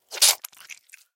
Пиявки - Альтернативный вариант